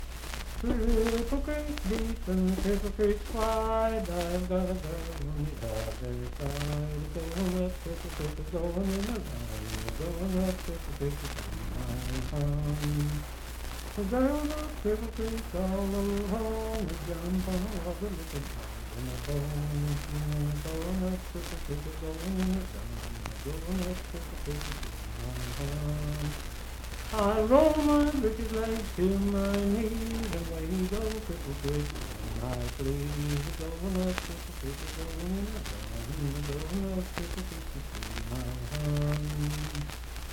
Unaccompanied vocal music
Dance, Game, and Party Songs
Voice (sung)
Randolph County (W. Va.)